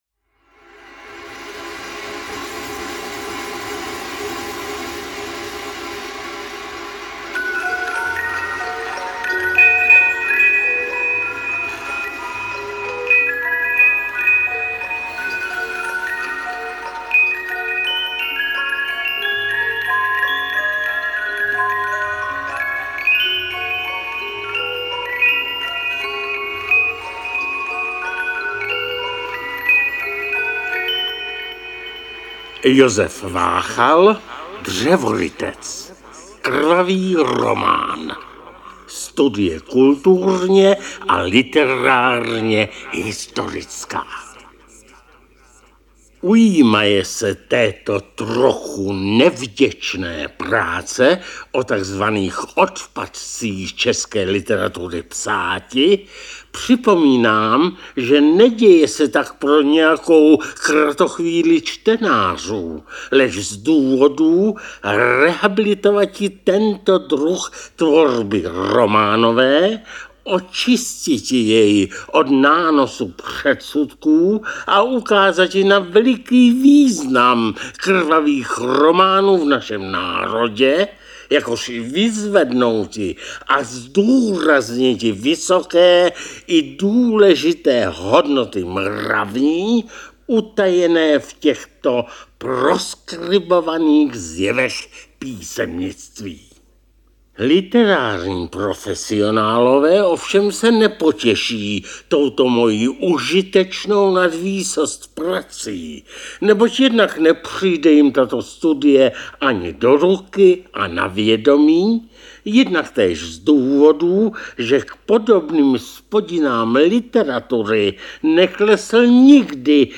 V roce 1994 vyšel Krvavý román v Pasece ve zvukové podobě na třech kazetách, geniálně namluvený Leošem Suchařípou ve studiu Českého rozhlasu Brno. Nezaměnitelný hlas tohoto herce a překladatele přidal novou hodnotu Váchalově svébytné ironii a humoru.
Procítění, s jakým předčítá tuto knihu L. Suchařípa, umocňuje její sveřepý humor takovým způsobem, že kdybych si měl vybrat jenom jednu audioknihu, byl by to právě Váchalův Krvavý román.